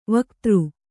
♪ vaktř